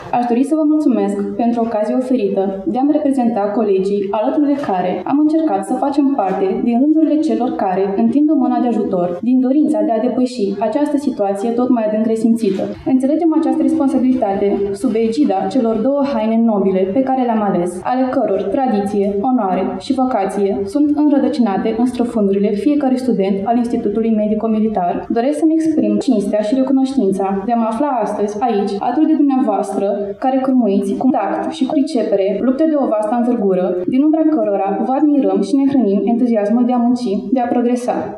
La ceremonia de astăzi din Sala de Oglinzi a Palatului Culturii din Tg. Mureș, de cea mai prestigioasă distincție a județului Mureș, “Fibula de la Suseni”, au beneficiat cinci medici de prestigiu, o asistentă medicală și o studentă la medicină militară.